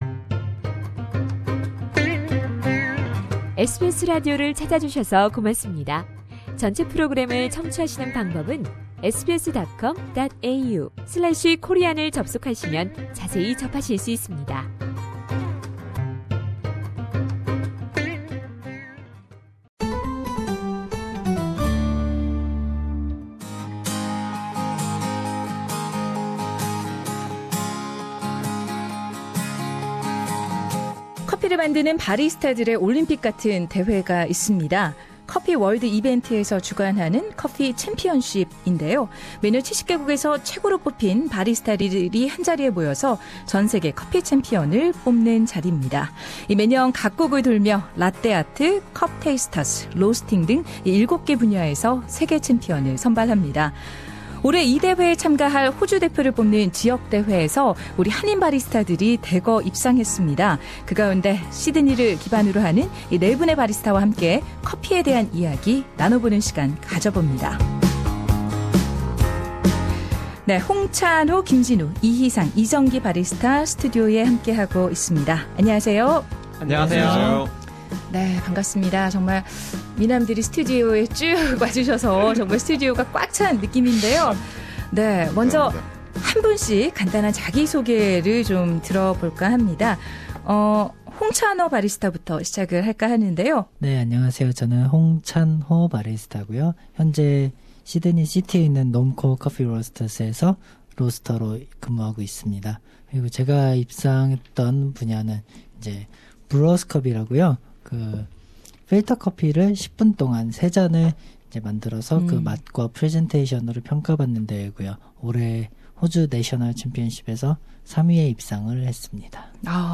호주의 커피챔피언쉽에서 입상한 한인 바리스타 4인과 함께 커피에 대한 이야기를 나눠본다.